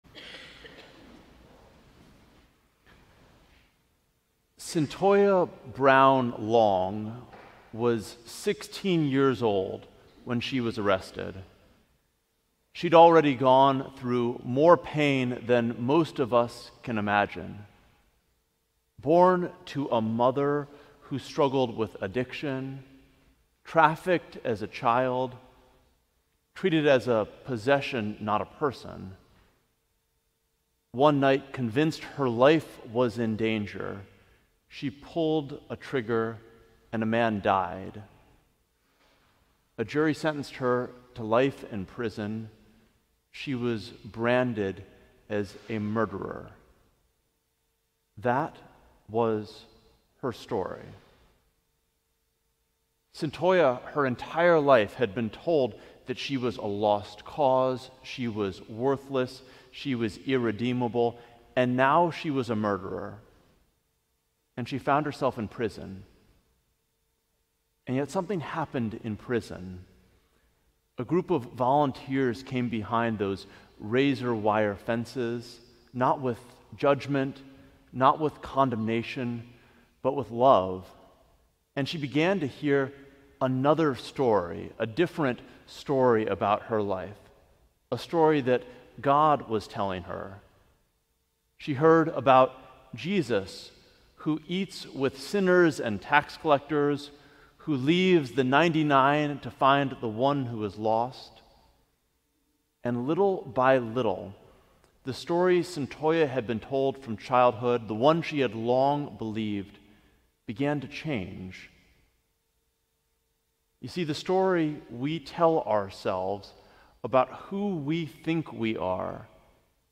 Sermon: Passport of Grace